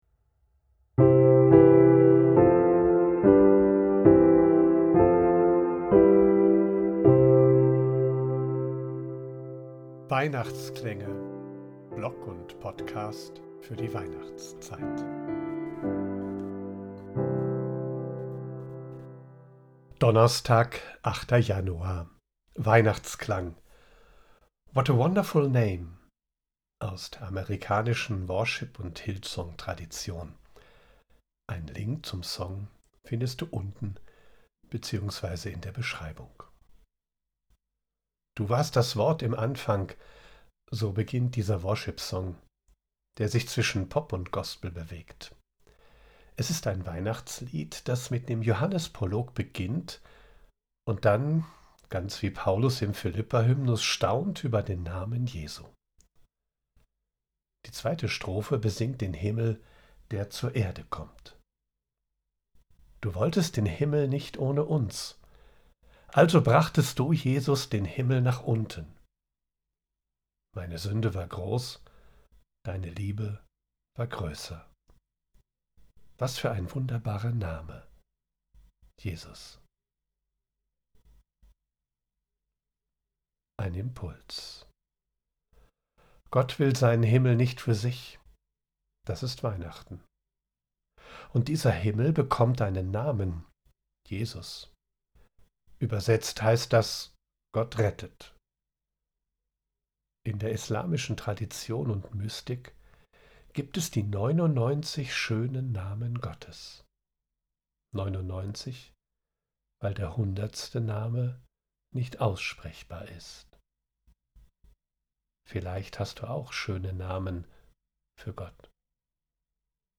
00:00:00 Weihnachtsklang: What a wonderful name